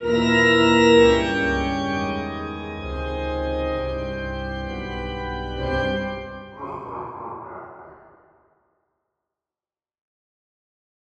GameOver.wav